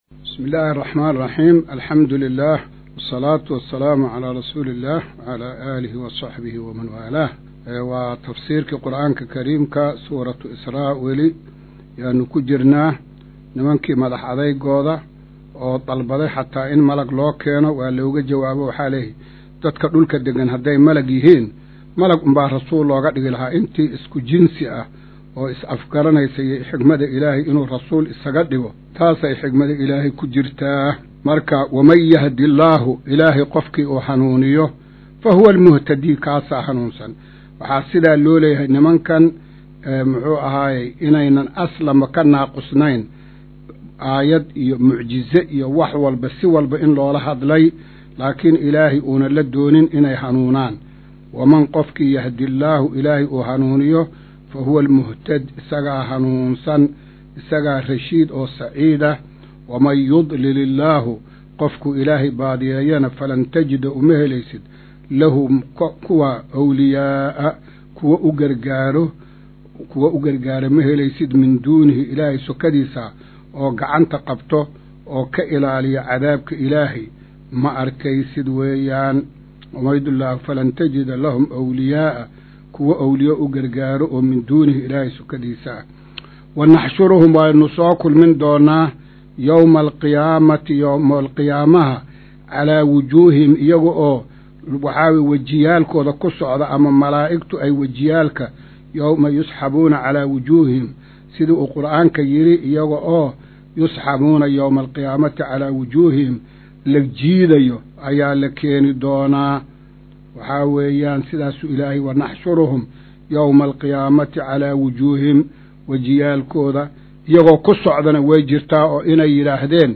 Maqal:- Casharka Tafsiirka Qur’aanka Idaacadda Himilo “Darsiga 142aad”